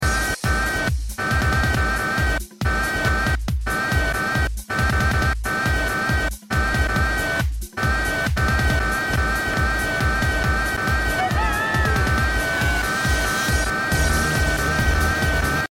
SCREAMING!!!